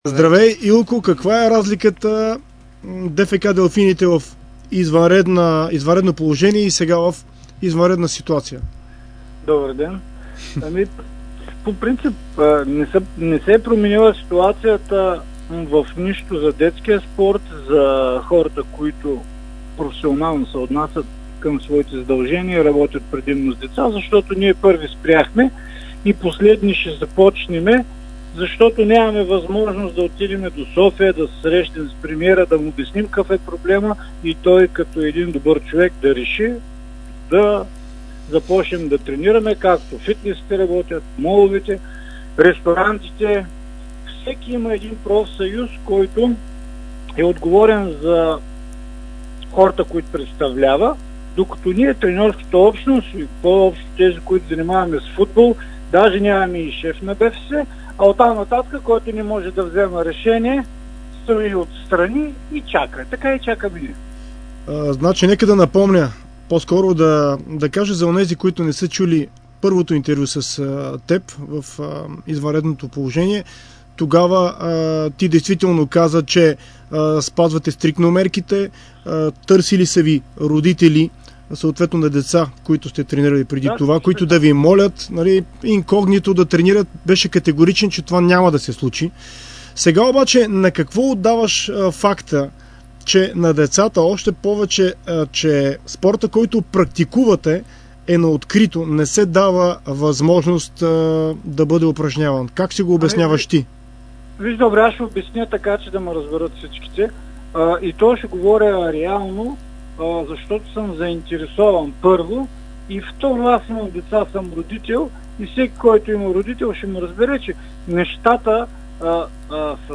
интервю за Дарик радио и dport